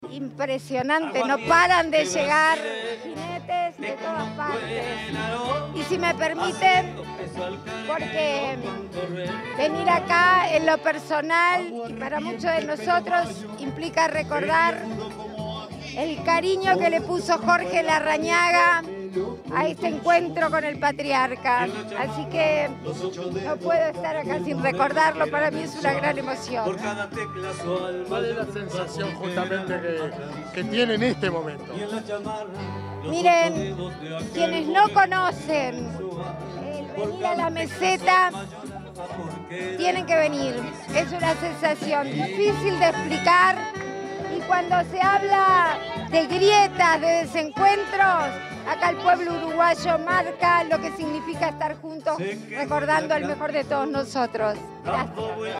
Declaraciones a la prensa de la presidenta en ejercicio, Beatriz Argimón
Declaraciones a la prensa de la presidenta en ejercicio, Beatriz Argimón 25/09/2022 Compartir Facebook X Copiar enlace WhatsApp LinkedIn La presidenta de la República ejercicio, Beatriz Argimón, participó, este 25 de setiembre, en el 28.° Encuentro con el Patriarca, en la Meseta de Artigas, en Paysandú. Tras el evento, la jerarca realizó declaraciones a la prensa.